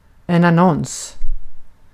Ääntäminen
IPA: /anˈɔns/ IPA: /anˈɔŋs/